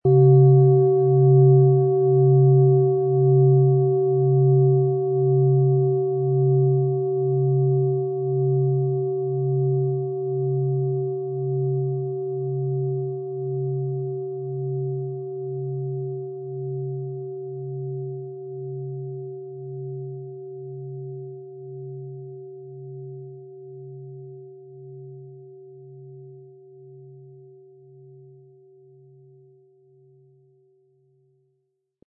OM Ton
Handgefertigte OM-Ton Planetenschale
Mit einem Durchmesser von 20,1 cm erzeugt die Klangschale einen kraftvollen und klaren Ton, der weit über das Persönliche hinausgeht.
MaterialBronze